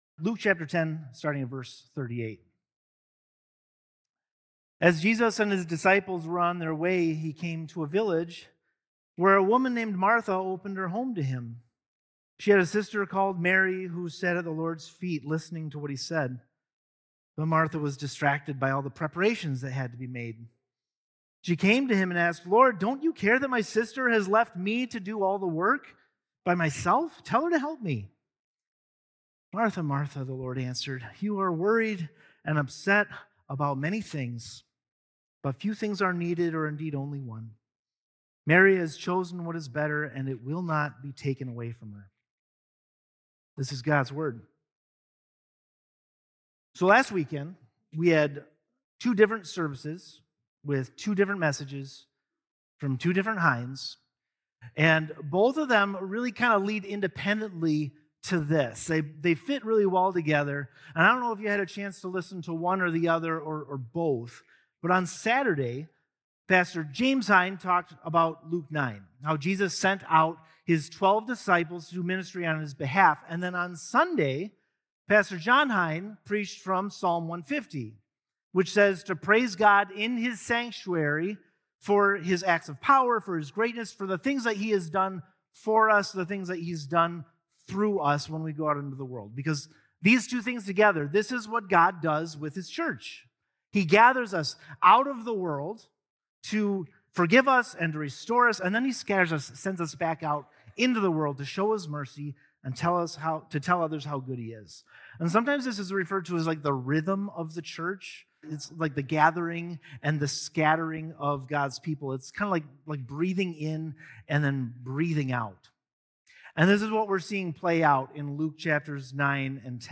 1 A Place of Hallelujah | Message for St. Marcus' 150th Church Anniversary 29:06